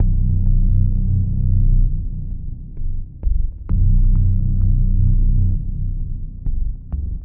Dark_FX.ogg